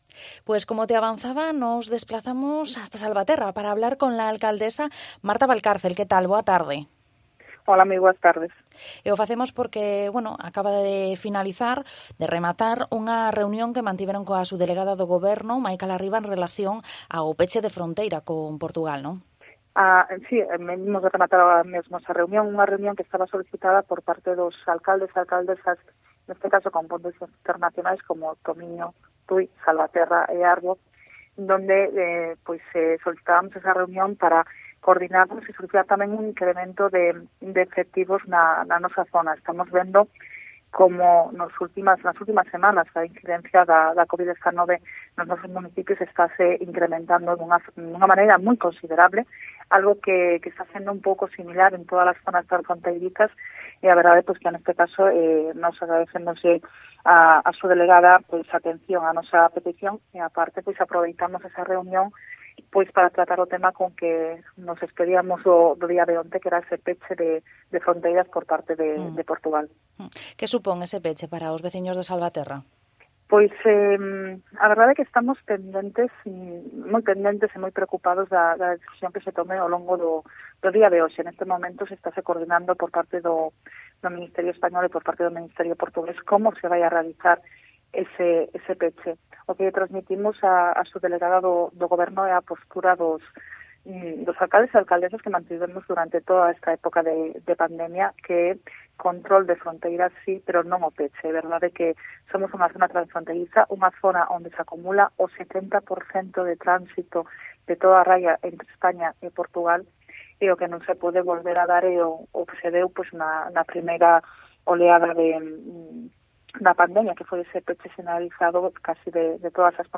En Cope hemos hablado con la Alcaldesa de Salvaterra do Miño, Marta Valcárcel, una vez finalizada la reunión Asegura que están de acuerdo que la implantación de controles en la frontera, pero asegura que el cierre es perjudicial para los vecinos.